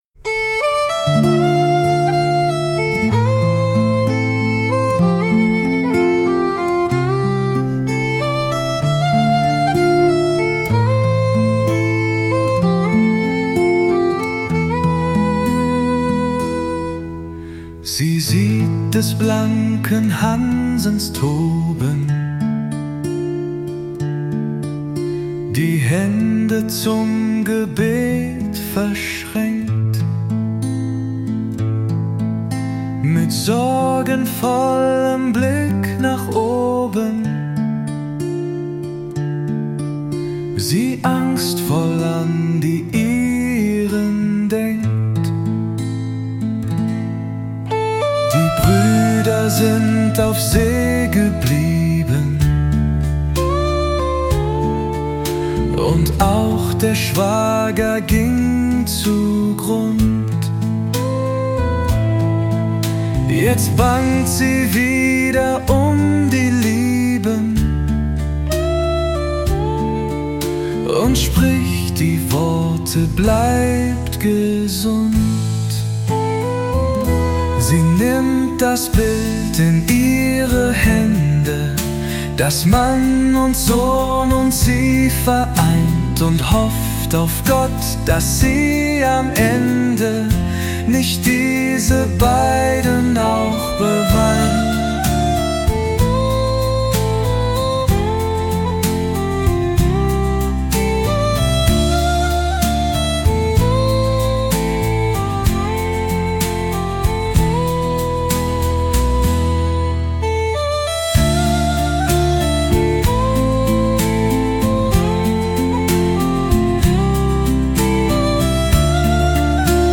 Der Text wurde mit einem bezahlten KI-Modell und entsprechenden Prompts zu folgendem Song umgesetzt: